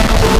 Player_UI [93].wav